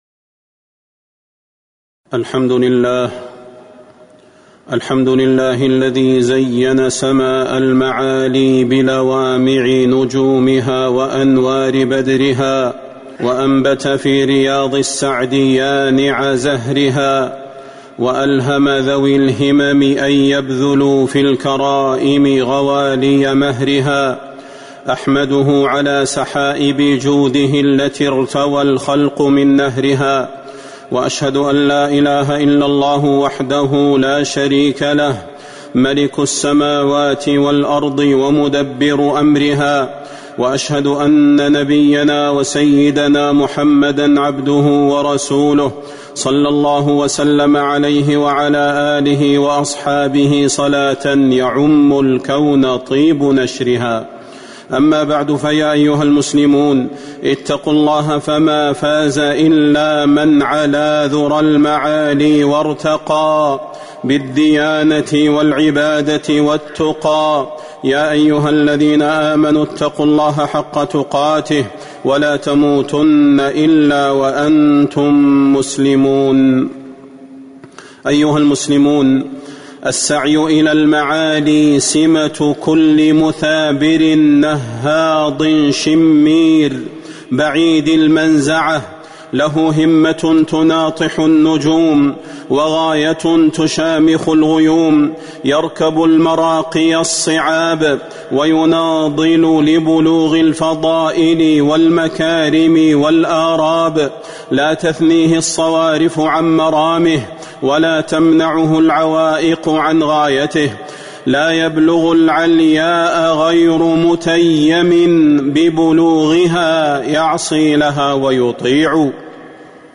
فضيلة الشيخ د. صلاح بن محمد البدير
تاريخ النشر ١٨ ذو القعدة ١٤٤٣ هـ المكان: المسجد النبوي الشيخ: فضيلة الشيخ د. صلاح بن محمد البدير فضيلة الشيخ د. صلاح بن محمد البدير السعي إلى المعالي